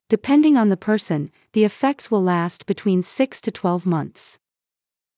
ﾃﾞｨﾍﾟﾝﾃﾞｨﾝｸﾞ ｵﾝ ｻﾞ ﾊﾟｰｿﾝ ｼﾞ ｴﾌｪｸﾂ ｳｨﾙ ﾗｽﾄ ﾋﾞﾄｩｳｨｰﾝ ｼｯｸｽ ﾄｩ ﾄｩｳｪﾙﾌﾞ ﾏﾝｽ